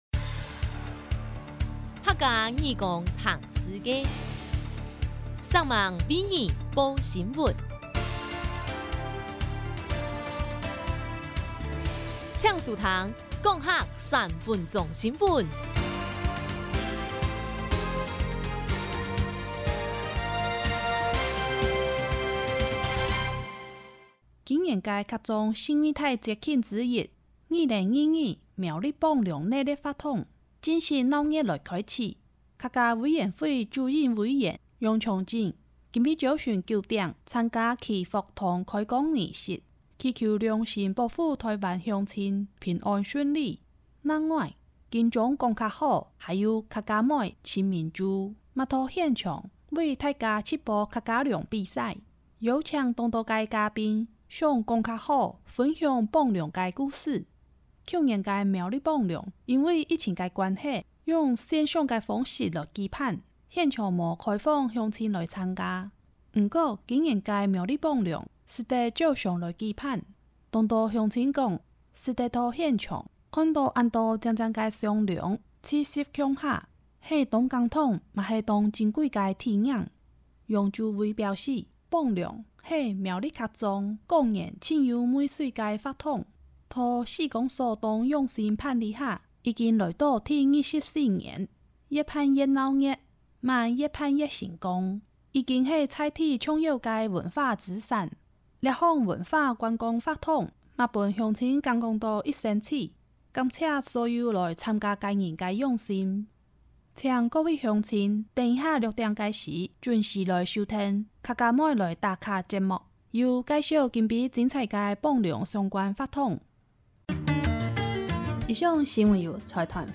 0209苗栗火旁龍新聞mp3.mp3